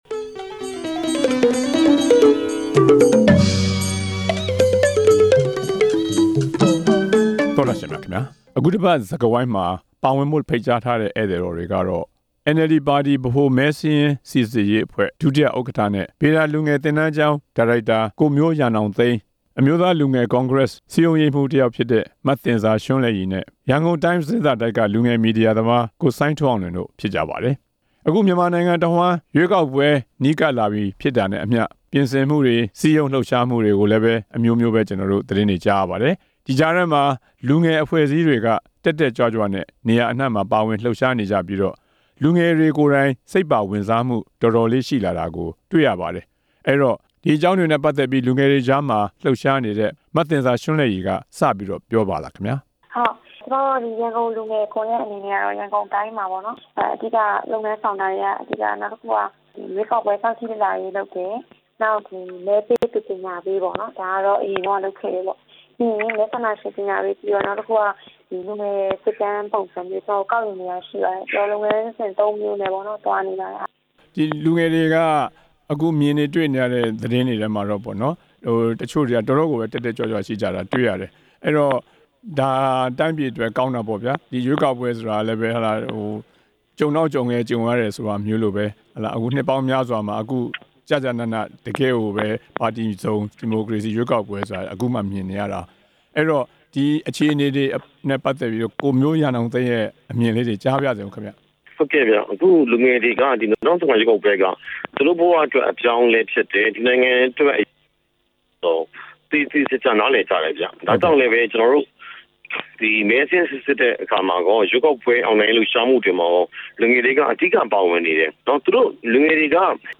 ရွေးကောက်ပွဲကို လူငယ်တွေ စိတ်ဝင်စားမှု အကြောင်း ဆွေးနွေးချက်